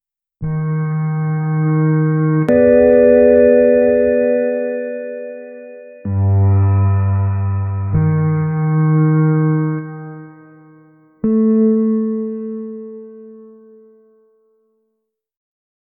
romantic time sound
romantic-time-sound--esmbwpj5.wav